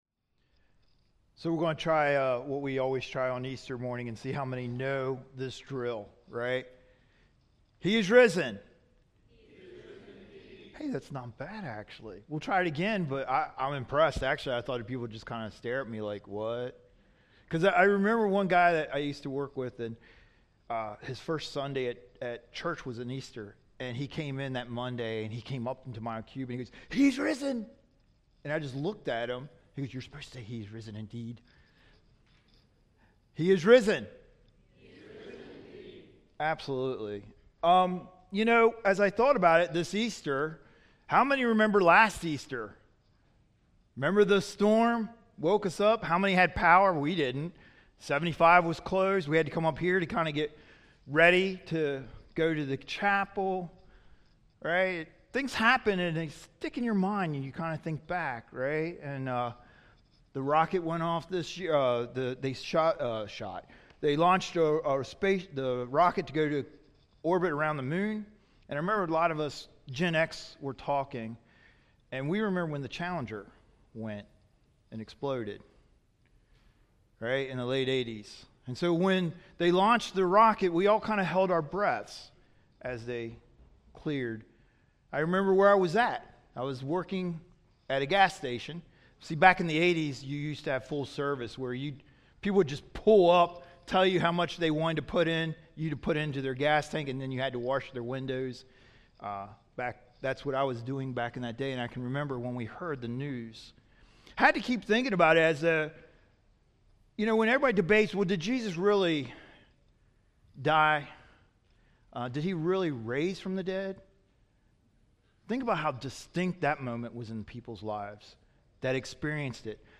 Sermon Series: i am Moses and the Burning Bush Exodus 3:14 (NLT) 14 God replied to Moses, “I am who i am.